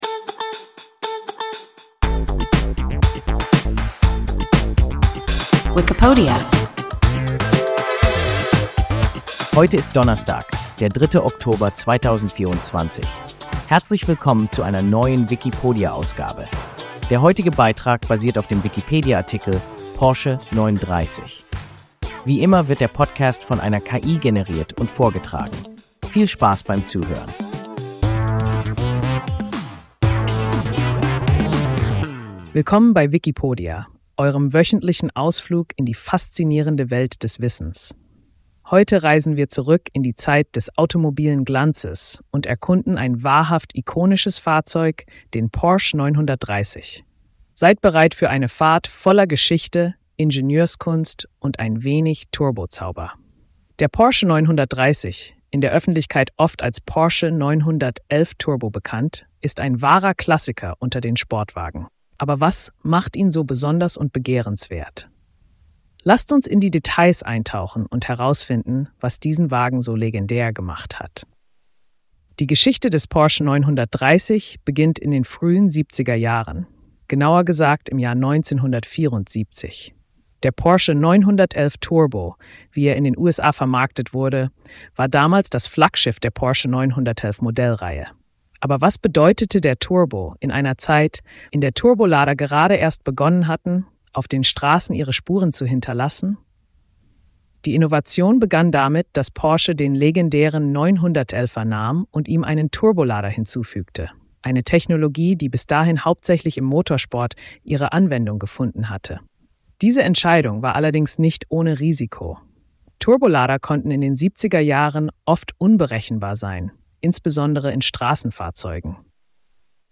Porsche 930 – WIKIPODIA – ein KI Podcast